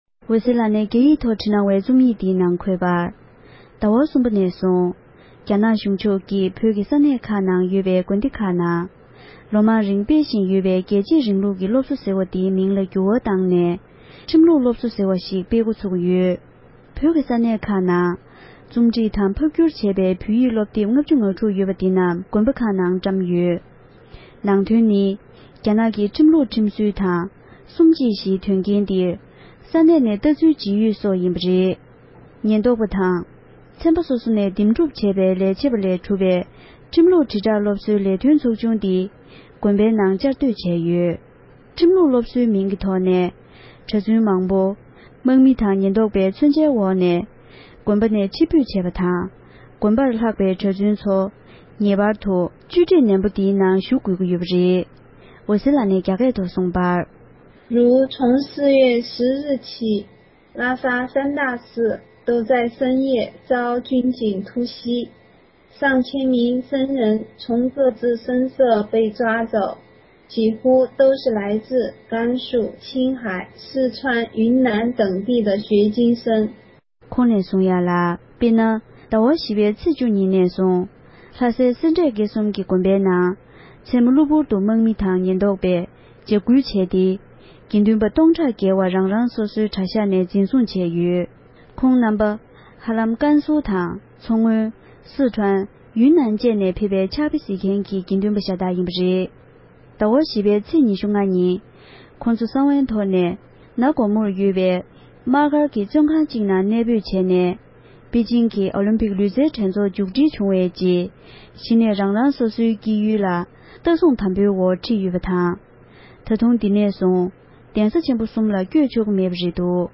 བོད་སྐད་ཐོག་ཕབ་བསྒྱུར་གྱིས་སྙན་སྒྲོན་ཞུས་པར་གསན་རོགས་གནང༌༎